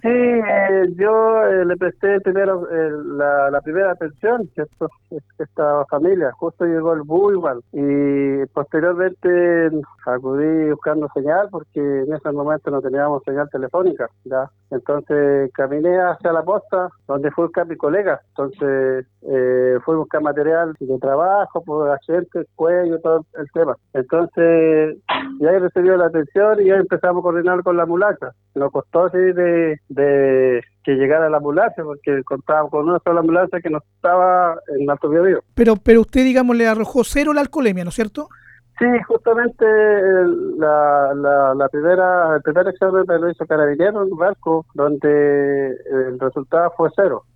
En diálogo con Radio Bío Bío, Tranamil señaló que perdió el control de la camioneta cuando enfrentó una curva e ingresó al viaducto.